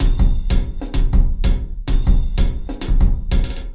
描述：奇数时间节拍120bpm
Tag: 回路 常规 时间 节奏 120BPM 节拍 敲击-loop 量化 鼓环 有节奏